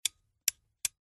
Sounds of lamps and luminaires